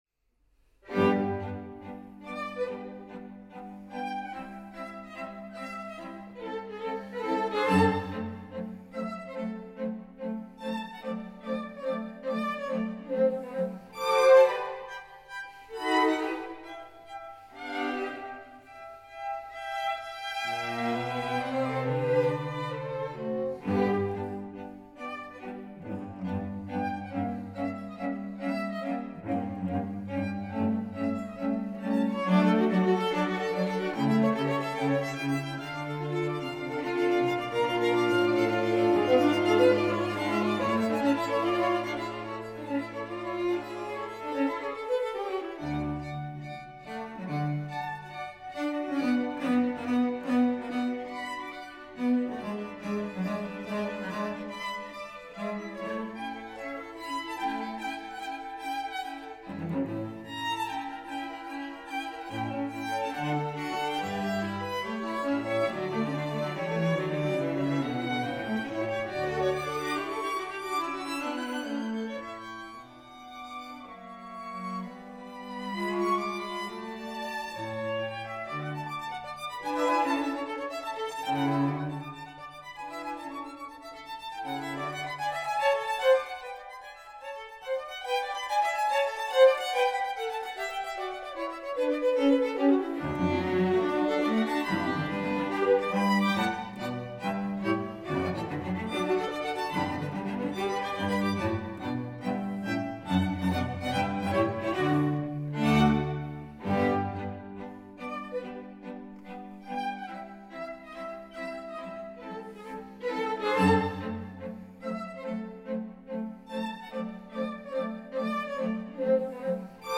Joseph Haydn Streichquartett op. 77 I Allegro Moderato by Arminio Quartett
Joseph-Haydn-Streichquartett-op.-77_I-Allegro-moderato-1.mp3